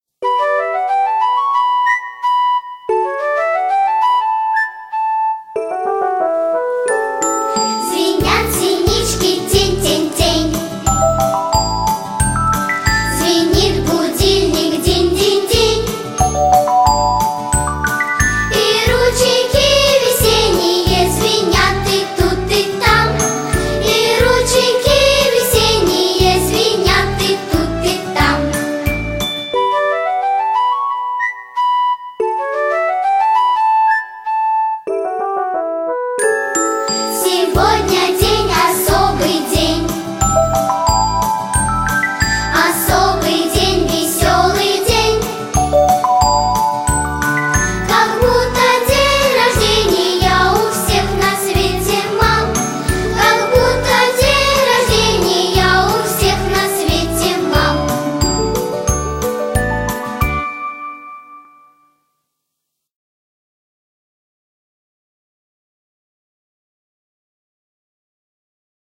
детская песенка к 8 Марта